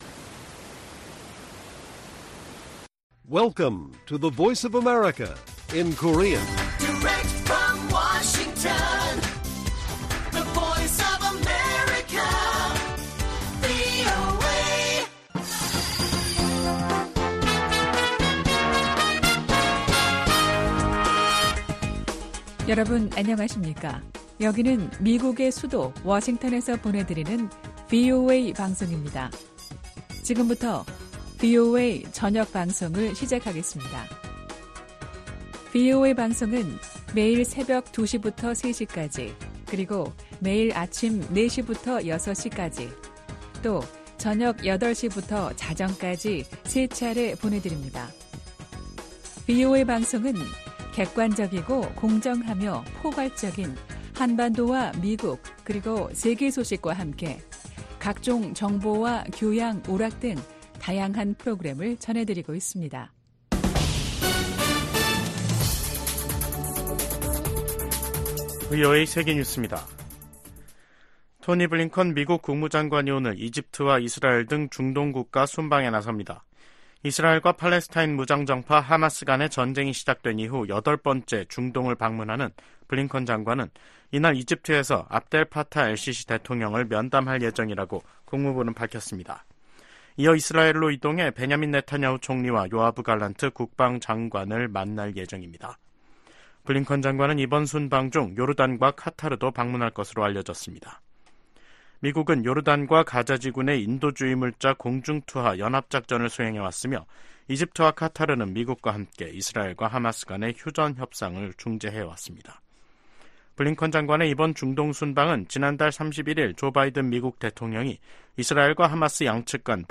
VOA 한국어 간판 뉴스 프로그램 '뉴스 투데이', 2024년 6월 10일 1부 방송입니다. 김여정 북한 노동당 부부장은 한국이 전단 살포와 확성기 방송을 병행하면 새로운 대응에 나서겠다고 위협했습니다. 한국 정부는 한국사회에 혼란을 야기하는 북한의 어떤 시도도 용납할 수 없다고 경고했습니다. 미국의 백악관 국가안보보좌관이 북한, 중국, 러시아 간 핵 협력 상황을 면밀이 주시하고 있다고 밝혔습니다.